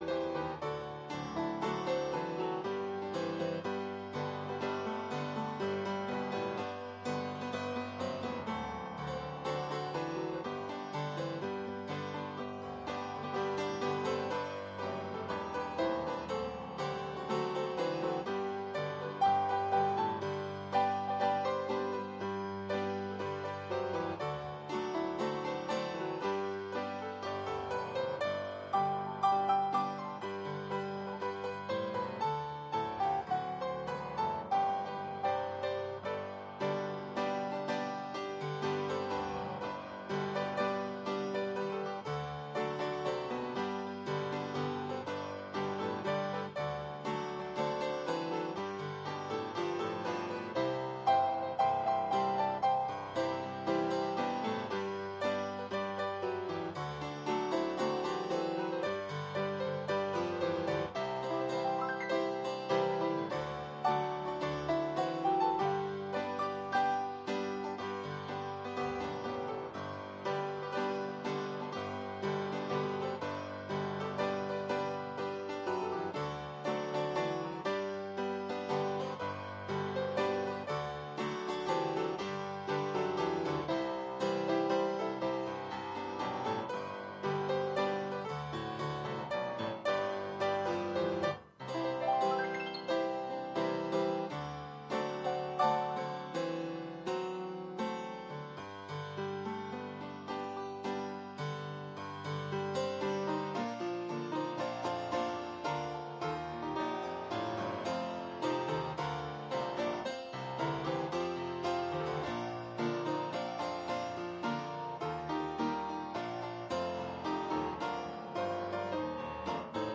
Mid Week Bible Study From Esther